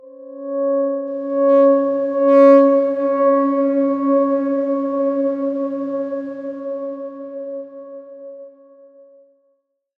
X_Darkswarm-C#4-f.wav